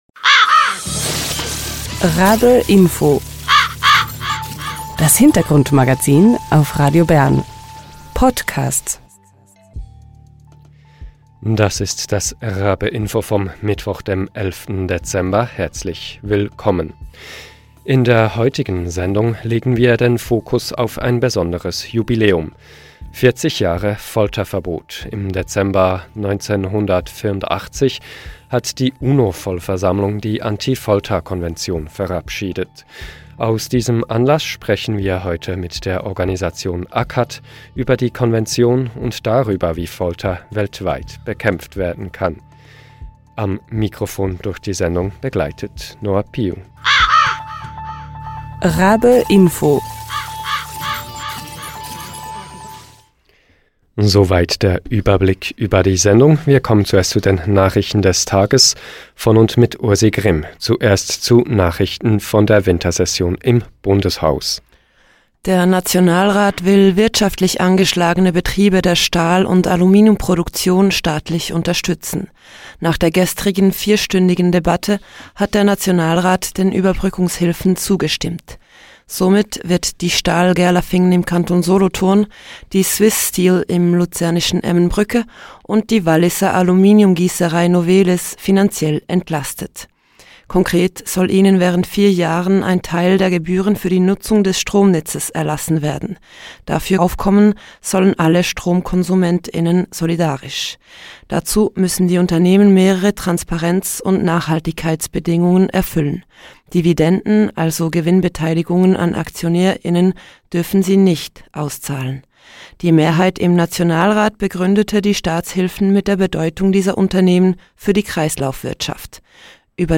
Vor 40 Jahren – im Dezember 1984 – wurde von der UNO-Vollversammlung die Anti-Folter-Konvention verabschiedet. Aus diesem Grund widmen wir uns eine Sendung lang dem Thema Folter und der Geschichte des Folterverbots. Wir sprechen dazu live in der...